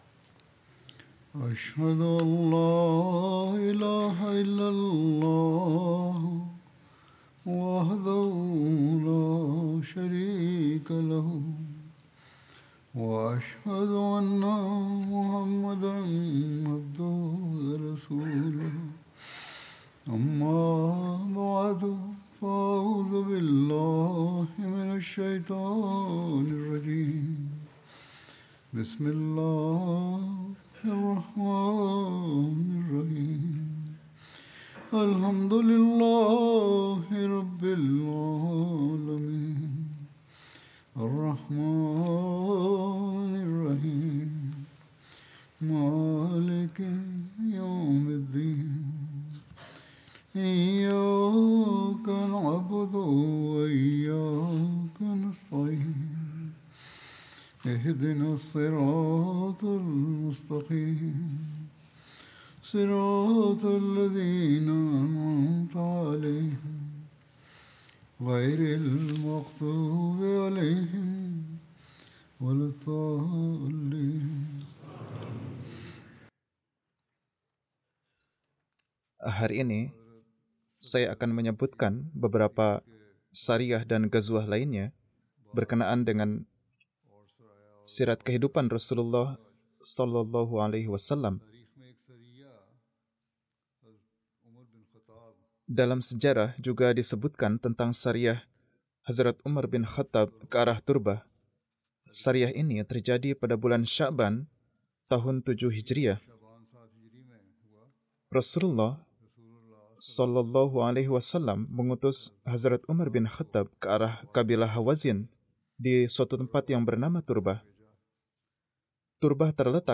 Indonesian Translation of Friday Sermon delivered by Khalifatul Masih